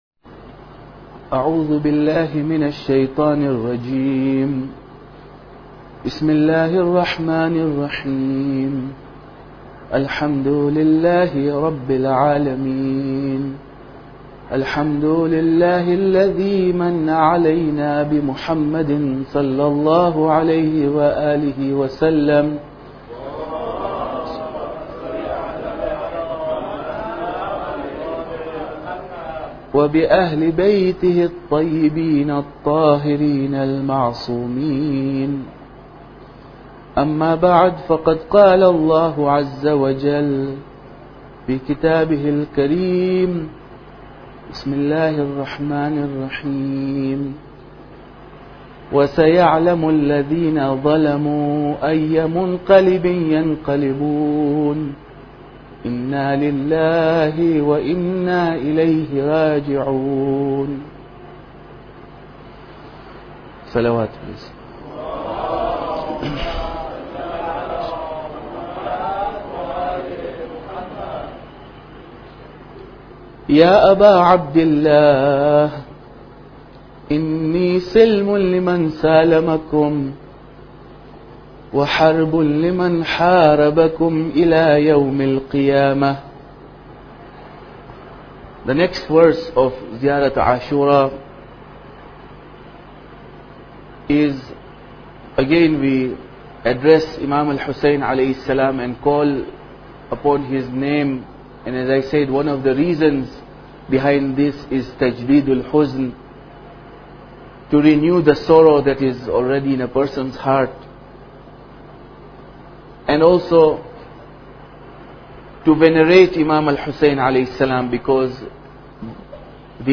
Muharram Lecture Laylatul Wahsha 11